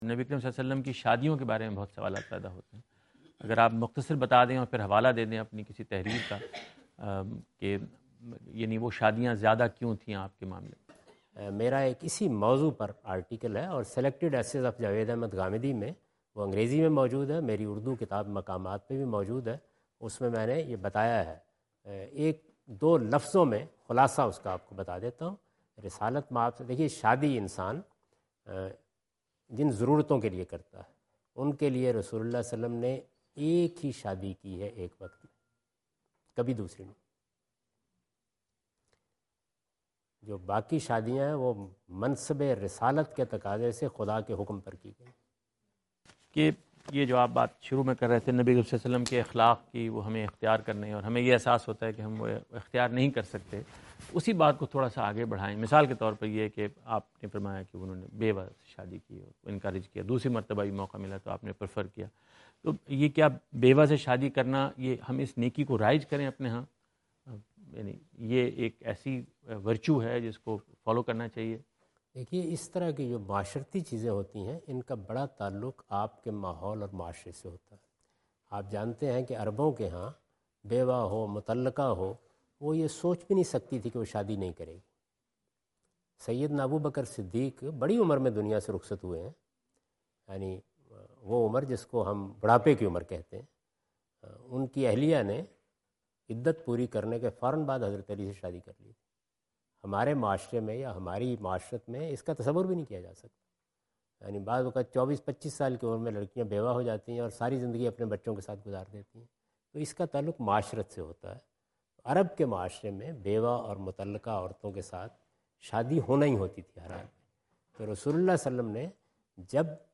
Category: English Subtitled / Questions_Answers /
جاوید احمد غامدی اپنے دورہ امریکہ2017 کے دوران فلیڈیلفیا میں "رسول اللہ ﷺ کی شادیاں اور ان کا مقصد" سے متعلق ایک سوال کا جواب دے رہے ہیں۔